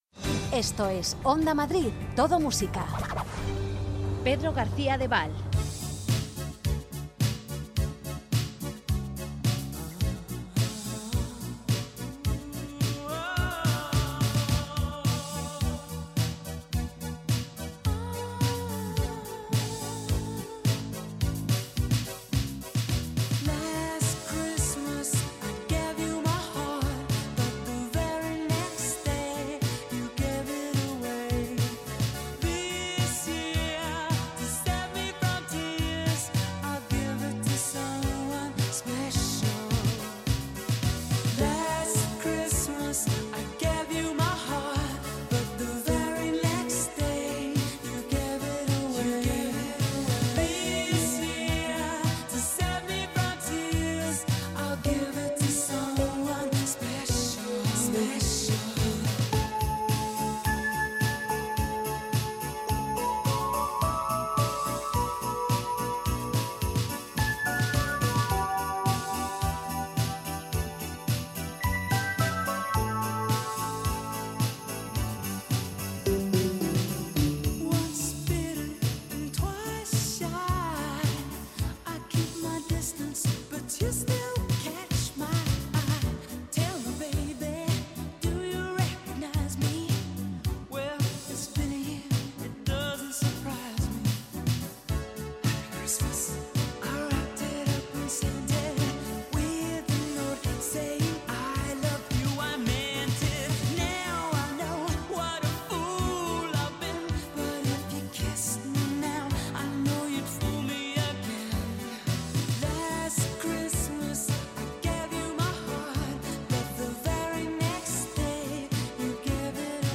Viaja con nuestros lentos a diferentes momentos de tu vida en las madrugadas de Onda Madrid Todo Música